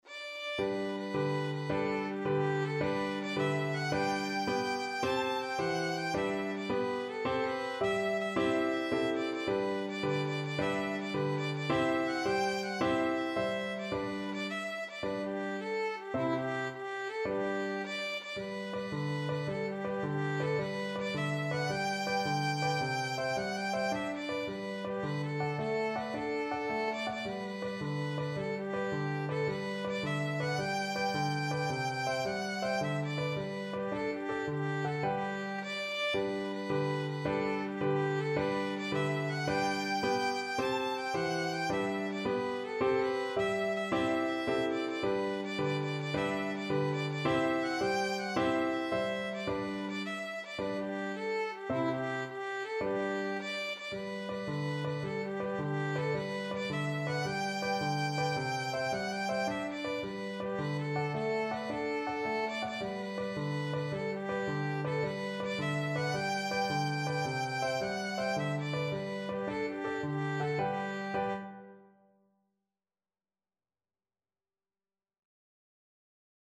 Violin
G major (Sounding Pitch) (View more G major Music for Violin )
6/8 (View more 6/8 Music)
Allegro moderato .=c.108 (View more music marked Allegro)
Traditional (View more Traditional Violin Music)
seven_drunk_nights_VLN.mp3